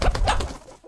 combat / creatures / chicken / he / attack2.ogg